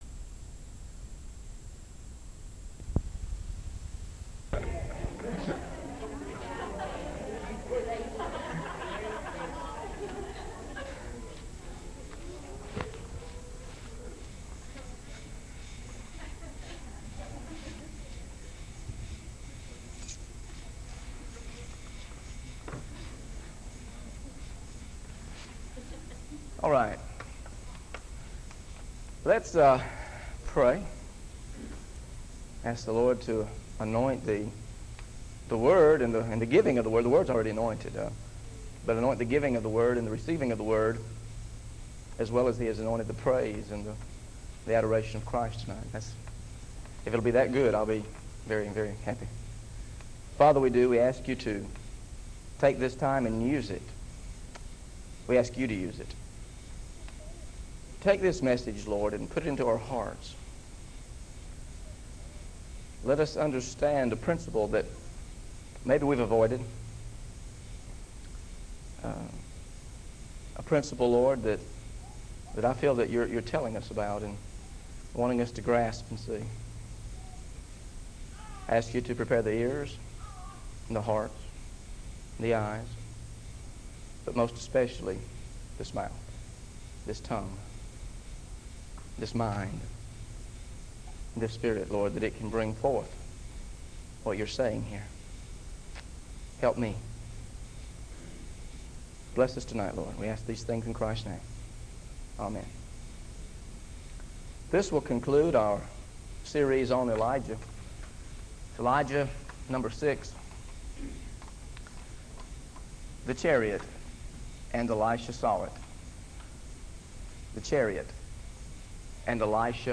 The Chariot and Elijah: How to Pass on Your Spirit Audio Scriptures used in this lesson: